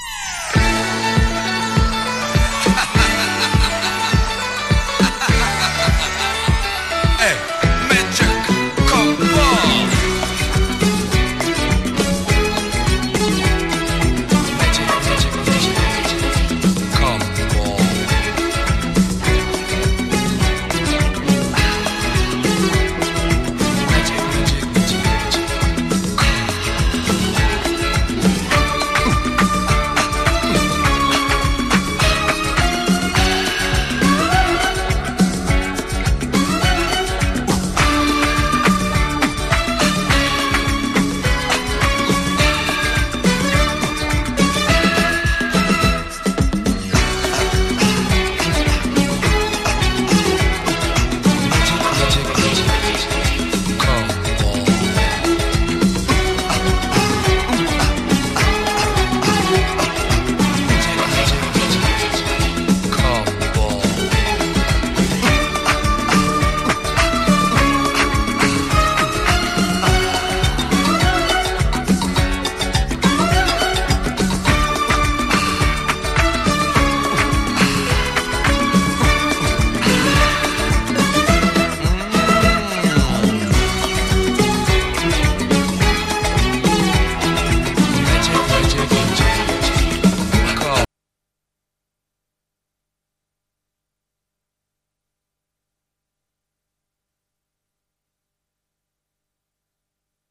カラフルなアナログ・シンセ・リフに、打ったパーカッション＋艶やかなストリングスで盛り上げるポップなアフロ・ディスコ！